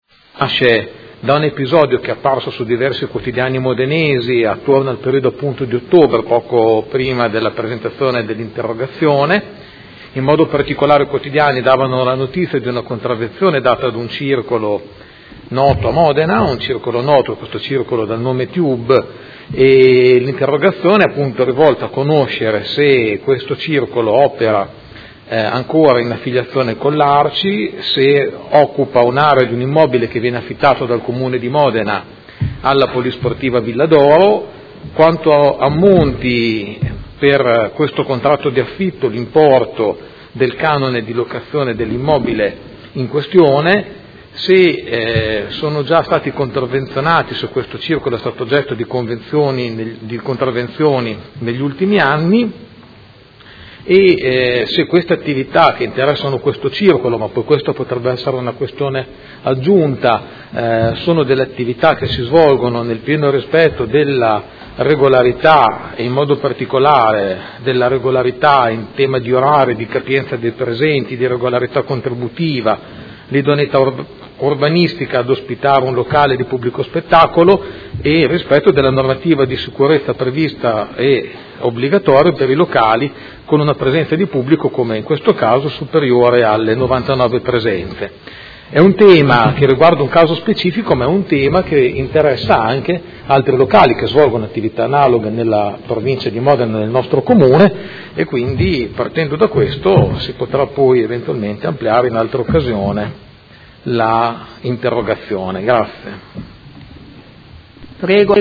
Seduta del 2/2/2017. Interrogazione del Consigliere Pellacani (F.I.) avente per oggetto: Regolarità Circolo TUBE